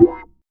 Snares
SNARE.98.NEPT.wav